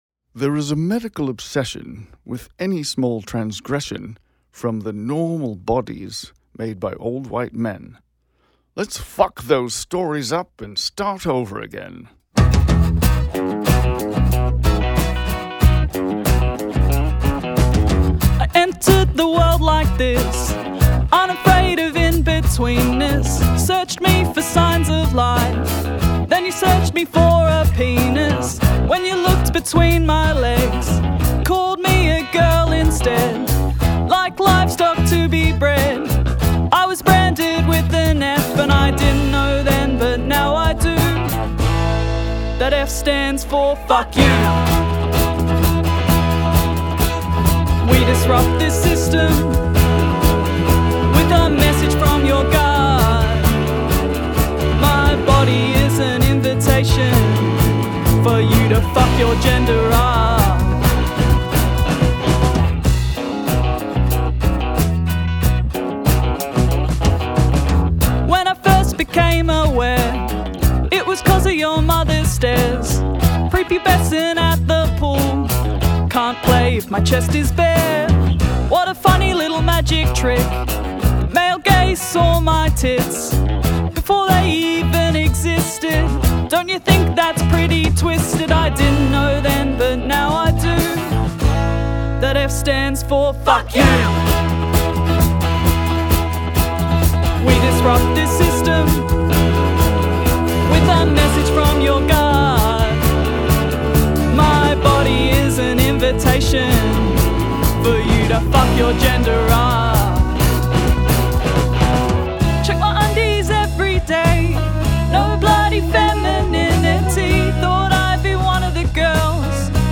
lead vocals, acoustic guitar
harmonies and background vocals
bass
electric guitar
drums, intro and outro vocal overdub